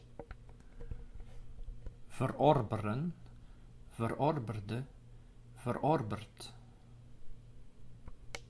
Ääntäminen
Synonyymit consumeren verteren verbruiken slopen opeten verschalken Ääntäminen Tuntematon aksentti: IPA: /vərˈɔrbərə(n)/ Haettu sana löytyi näillä lähdekielillä: hollanti Käännöksiä ei löytynyt valitulle kohdekielelle.